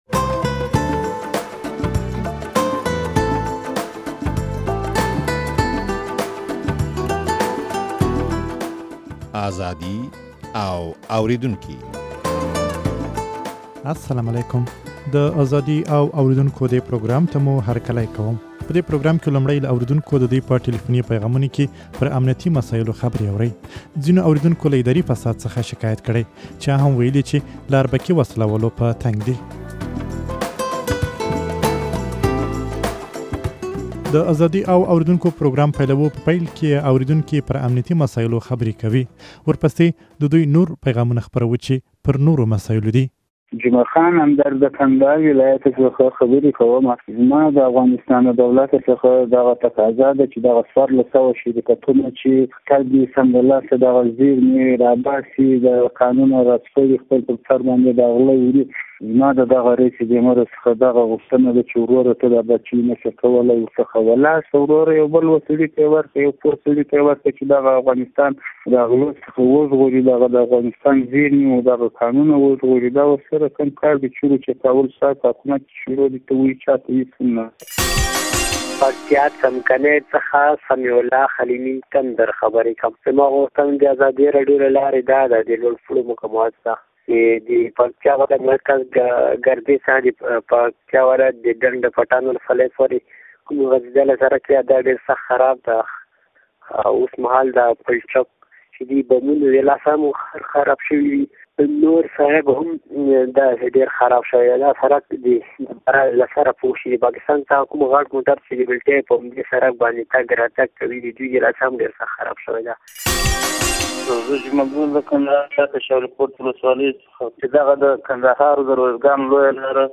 په دې پروګرام کې لومړى له اورېدونکو د دوى په ټليفوني پيغامونو کې پر امنيتي مسايلو خبرې اورئ.